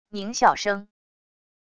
狞笑声wav音频